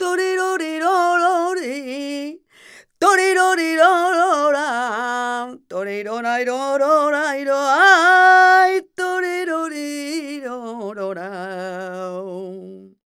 46b17voc-f#.aif